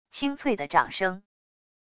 清脆的掌声wav音频